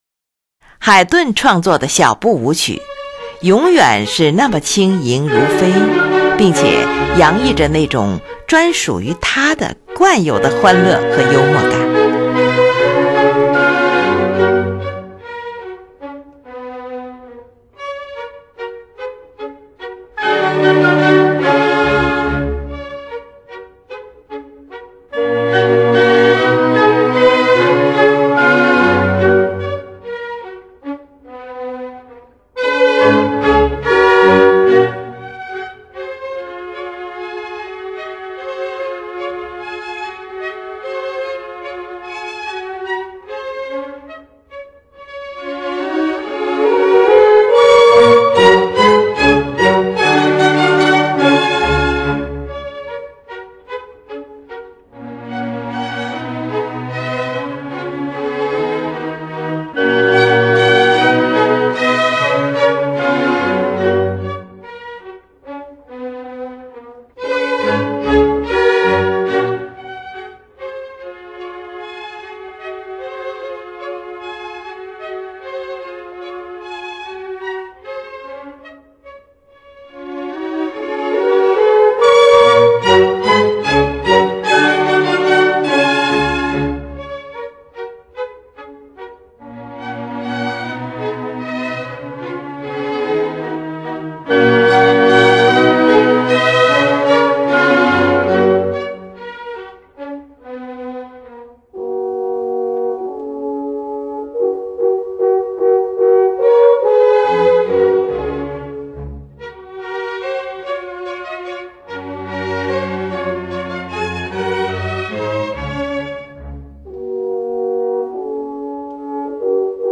03 Menuett 小步舞曲 320K/MP3
最后停留在一个三度音上，没有结束就直接转入到第四乐章。